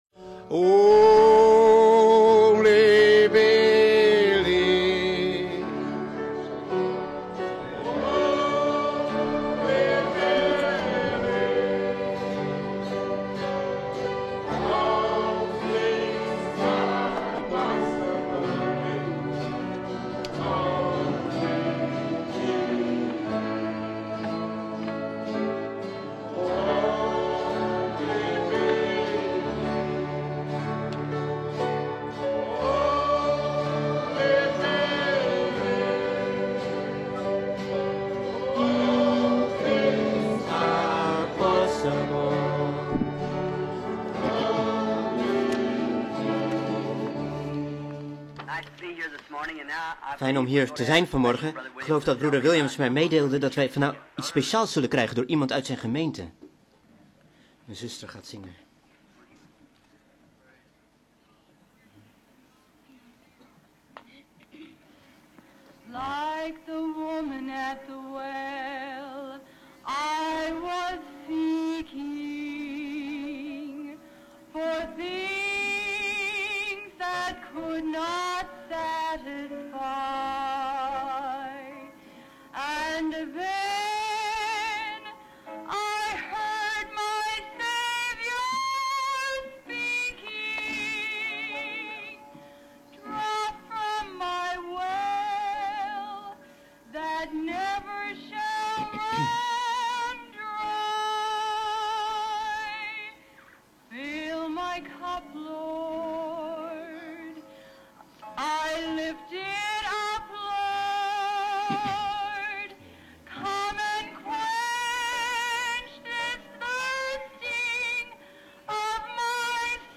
Vertaalde prediking "Why little Bethlehem" door William Marrion Branham te Ramada inn, Phoenix, Arizona, USA, 's ochtends op zaterdag 14 december 1963